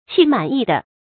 器满意得 qì mǎn yì dé
器满意得发音